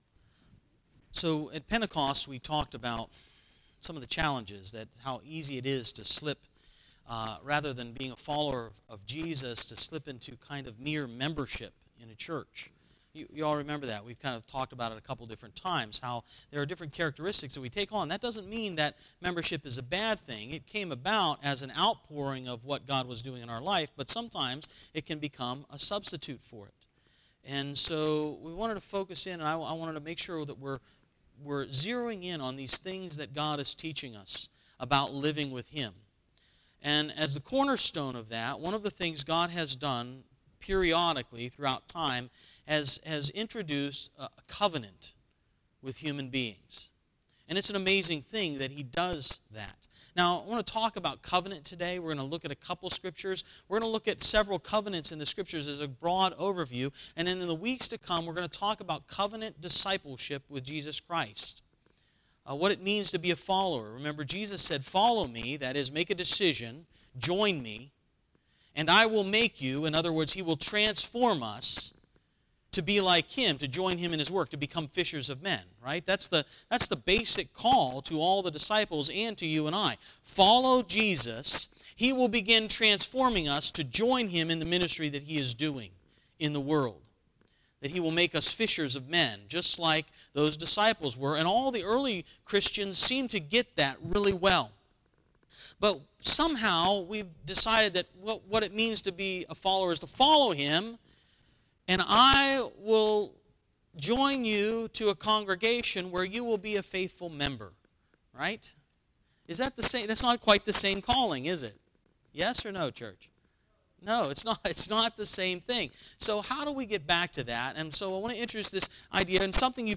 Sermon from 6-10-18